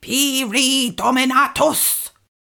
mandrake fvttdata/Data/modules/psfx/library/incantations/older-female/fire-spells/pyri-dominatus
pyri-dominatus-commanding.ogg